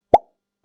音效包
Riser做短片開頭